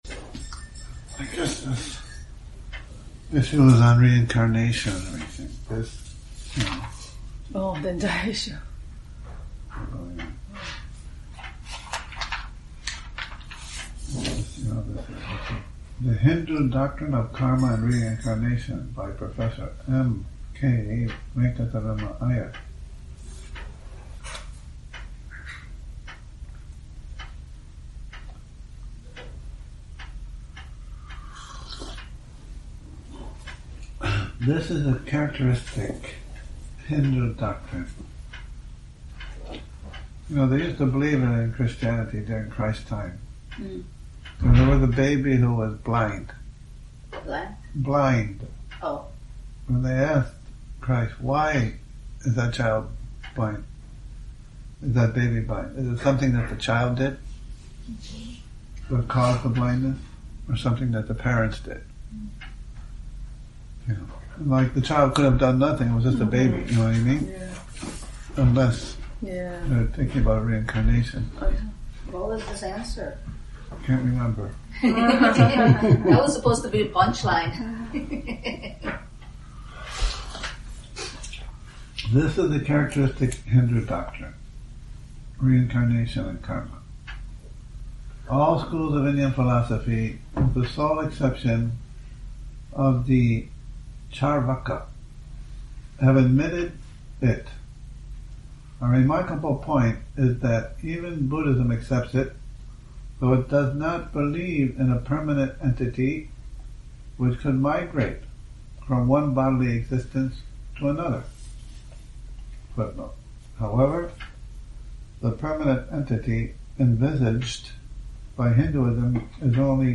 Morning Reading, 14 Nov 2019